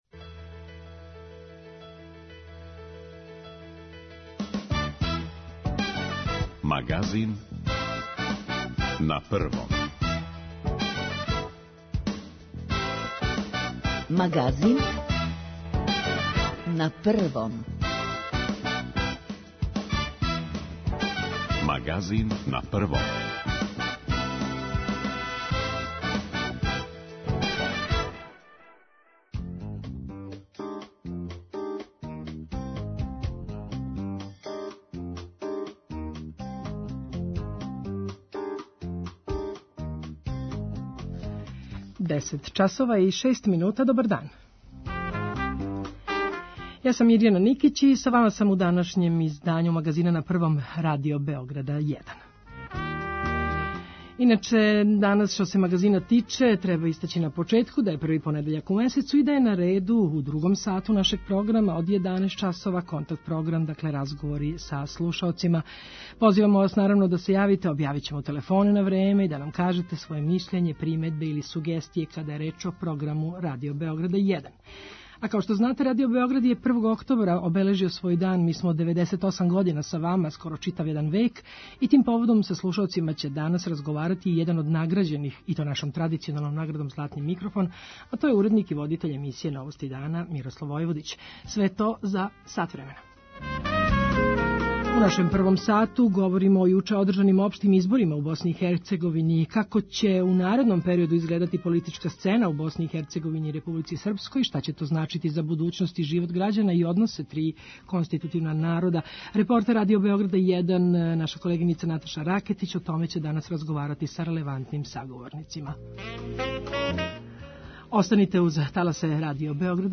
Контакт програм – разговори са слушаоцима